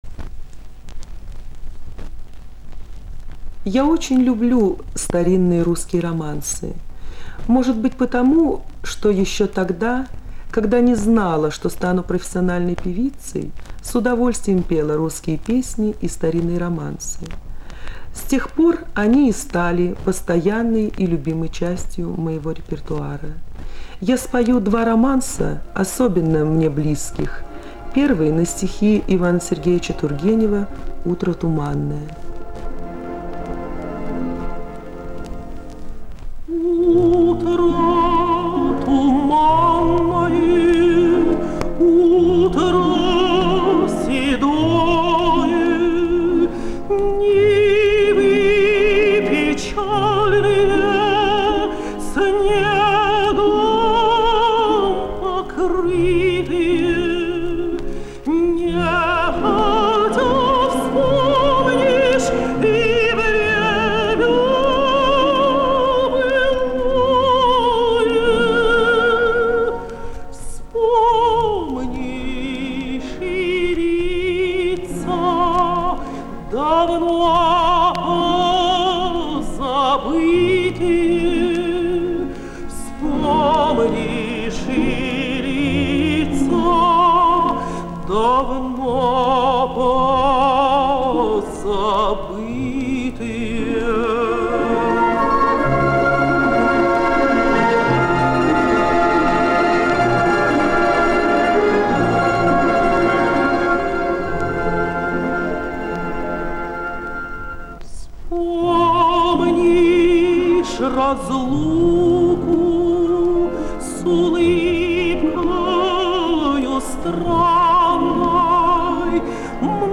Старинный русский романс
С исполнением романсов выступает солистка Большого театра Союза ССР Валентина Левко. Оперная и камерная певица, она значительную часть своего творчества посвящает песне и русскому романсу.
Звуковая страница 2 - Старинные романсы: "Утро туманное" (В.Абаза, И.Тургенев); "Я вас любил" (А.Пушкин). Поёт В.Левко.